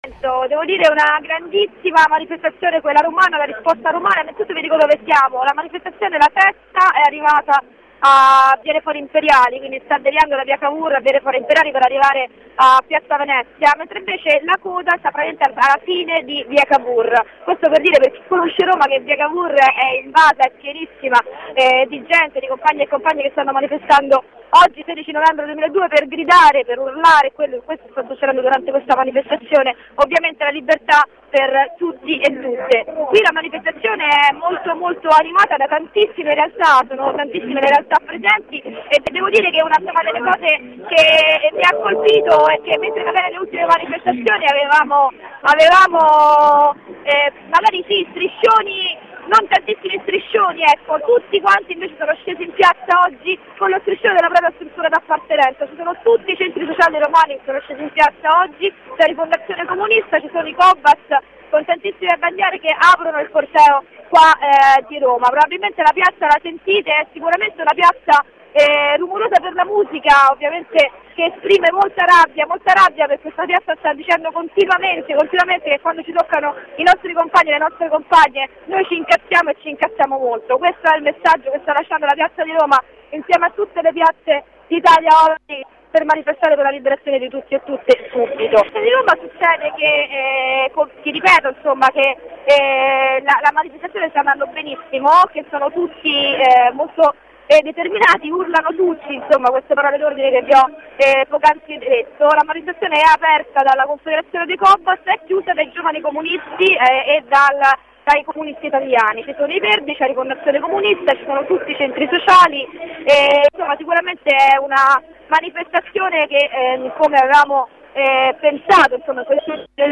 Dal corteo di roma, dove trentamila persone sono scese in piazza
Corrispondenza dal corteo di roma